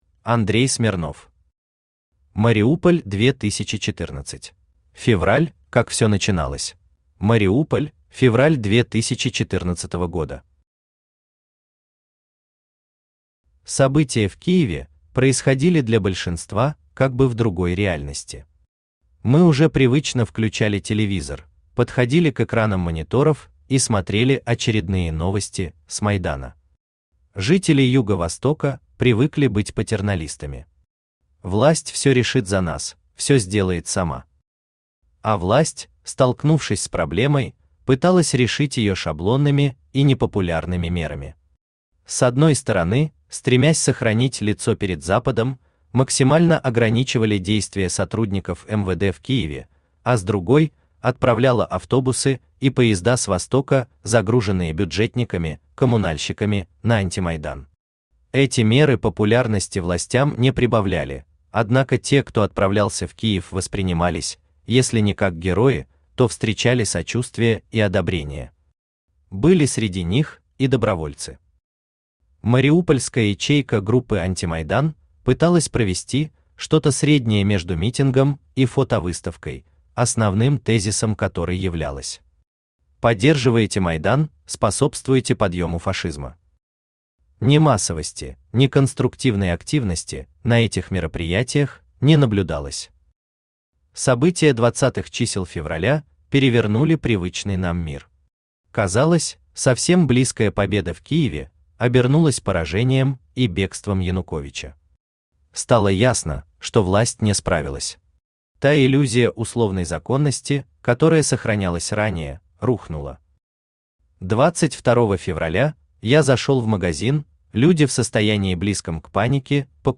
Аудиокнига Мариуполь 2014 | Библиотека аудиокниг
Aудиокнига Мариуполь 2014 Автор Андрей Смирнов Читает аудиокнигу Авточтец ЛитРес.